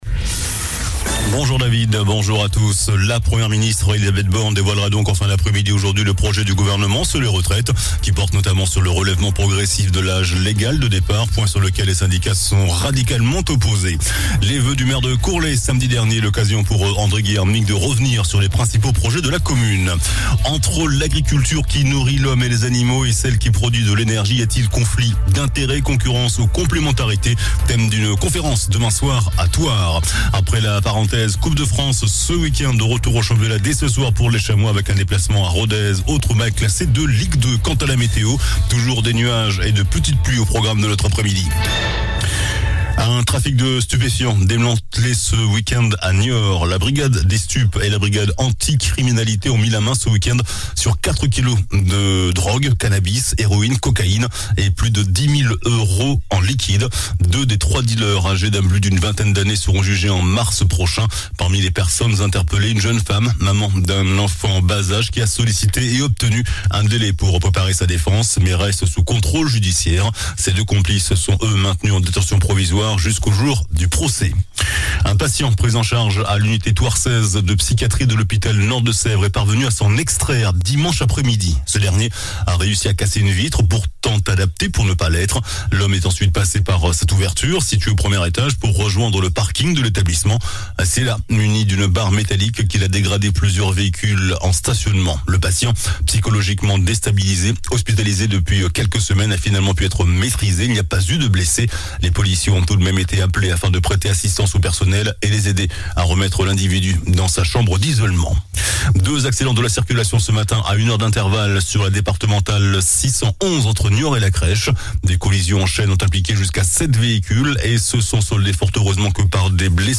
JOURNAL DU MARDI 10 JANVIER ( MIDI )